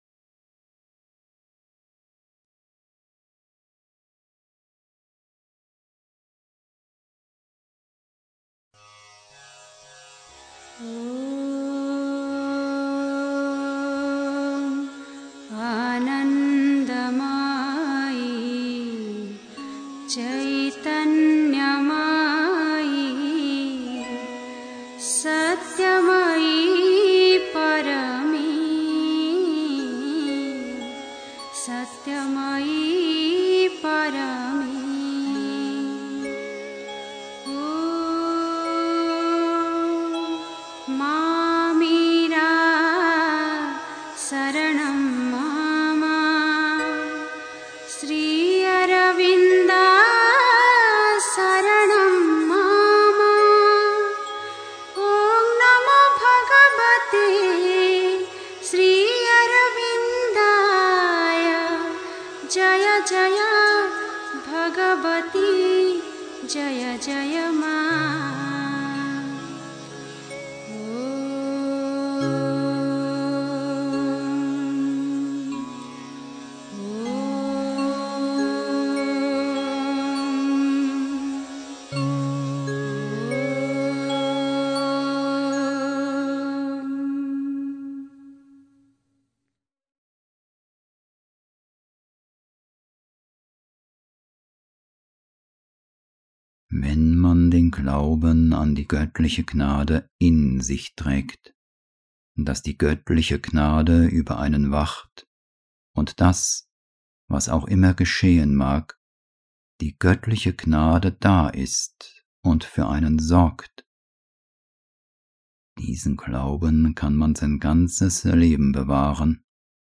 1. Einstimmung mit Musik. 2. Wenn man den Glauben an die göttliche Gnade in sich trägt (Die Mutter, CWM, Vol. 5, p. 297) 3. Zwölf Minuten Stille.